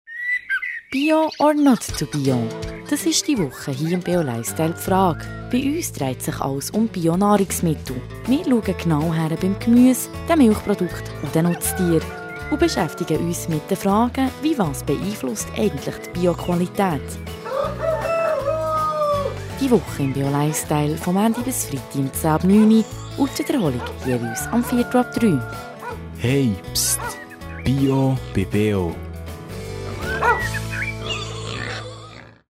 Sendehinweis zur Bio Woche im BeO-Lifestyle